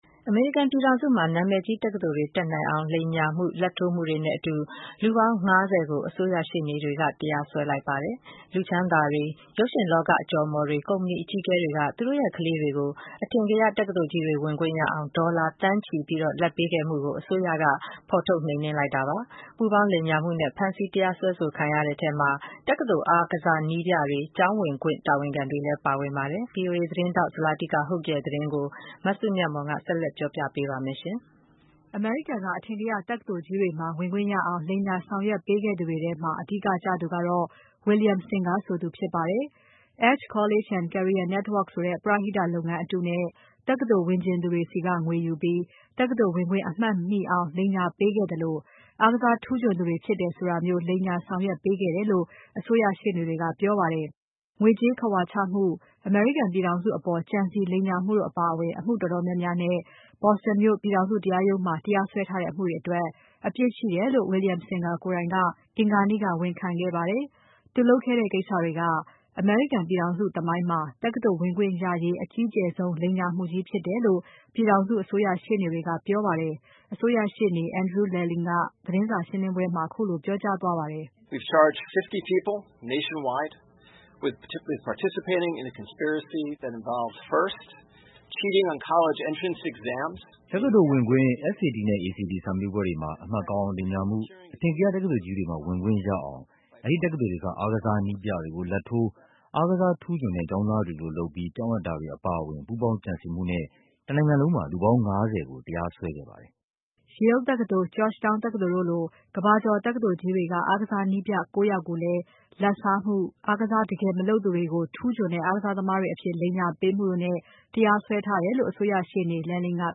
အစိုးရရှေ့နေ Andrew Lelling ကသတင်း စာရှင်းပွဲမှာ ခုလို ပြောသွားပါတယ်။